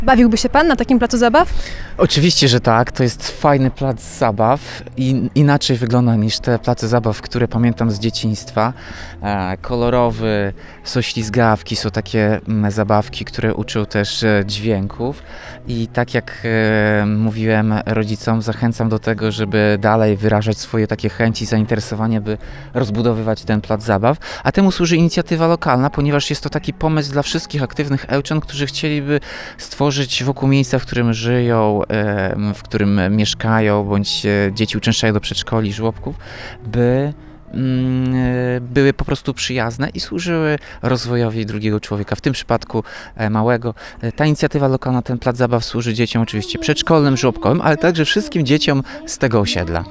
Plac zabaw przy Miejskim Przedszkolu i Żłobku „Ekoludki” powstał w ramach Inicjatywy Lokalnej. Do korzystania z programu zachęca wszystkich mieszkańców Tomasz Andrukiewicz, włodarz miasta.